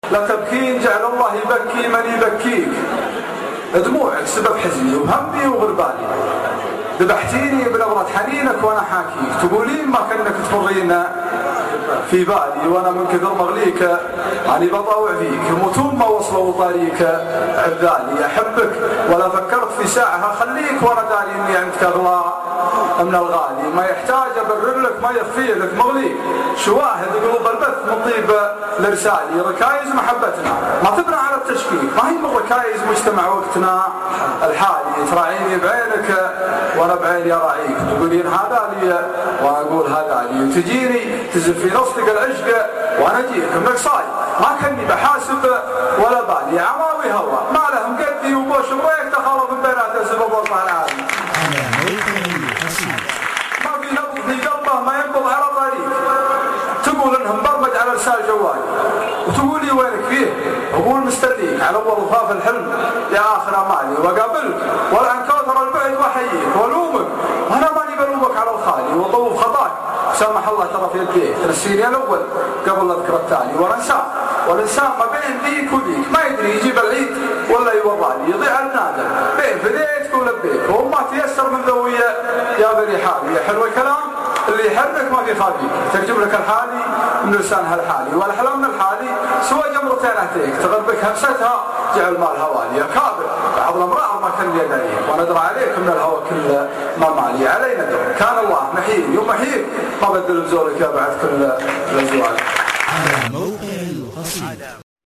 اصبوحة جامعة الكويت